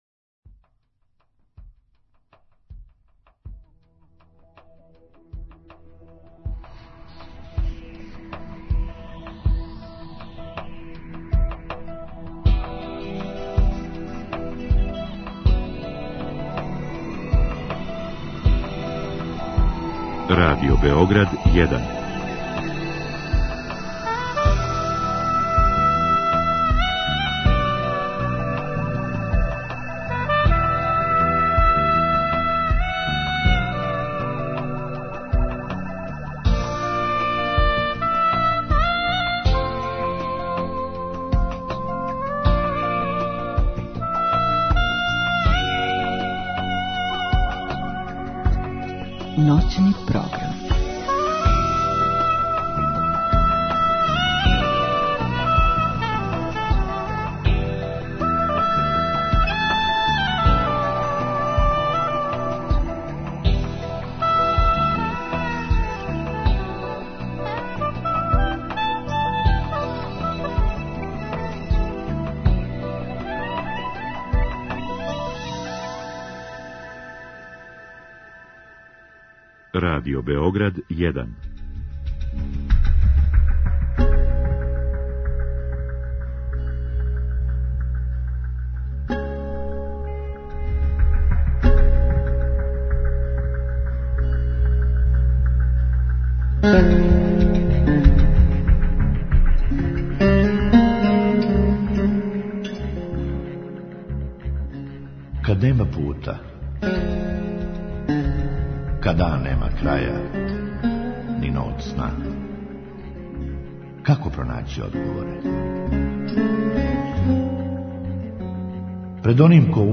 Други сат је намењен укључењима слушалаца који у директном програму могу поставити питање госту.